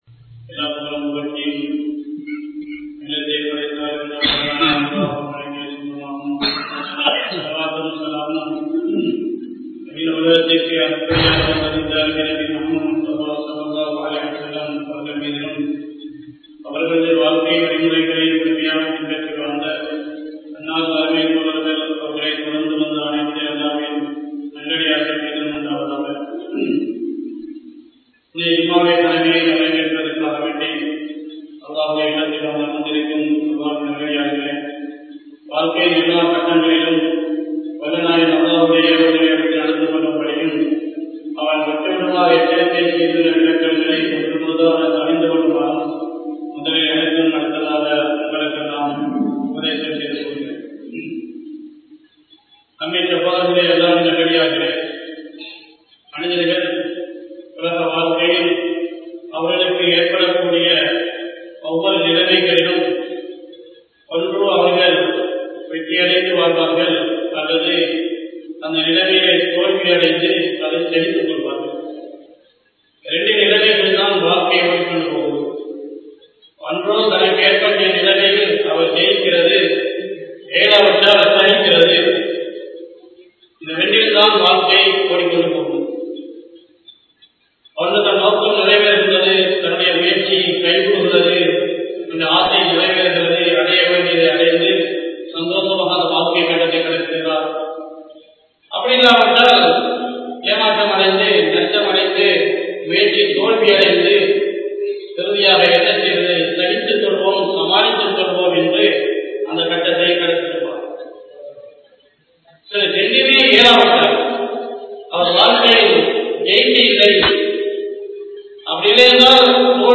Mumeenkalin Vetri Thaqwa(முஃமீன்களின் வெற்றி தக்வா) | Audio Bayans | All Ceylon Muslim Youth Community | Addalaichenai
Muneera Masjidh